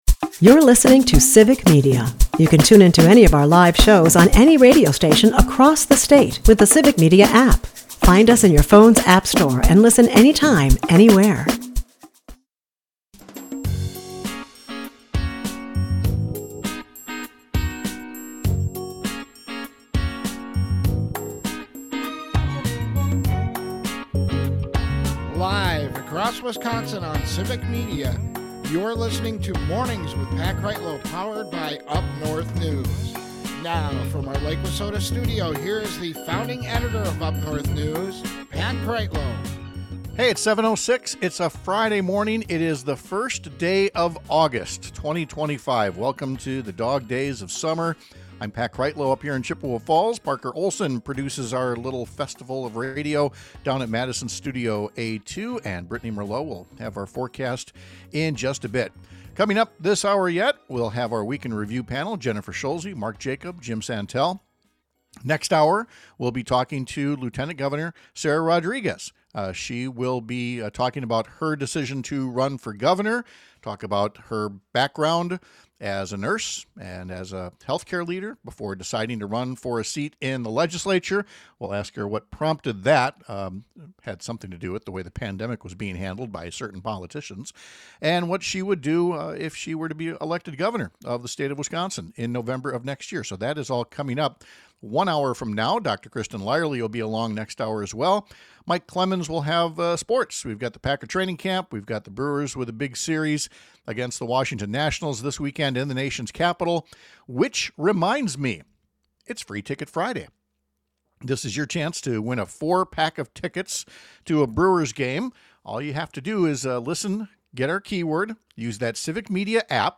We’ll review the top stories of the past few days with our Week In Review panel—including this morning’s newly announced tariffs from President Trump that make little sense and will only increase prices further for American consumers.